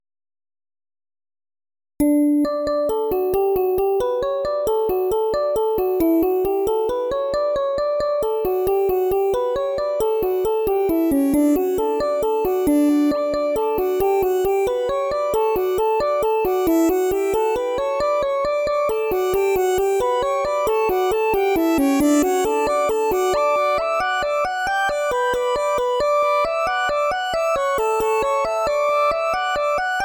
“Lo-Fi Cybertrad”